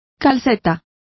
Complete with pronunciation of the translation of stockings.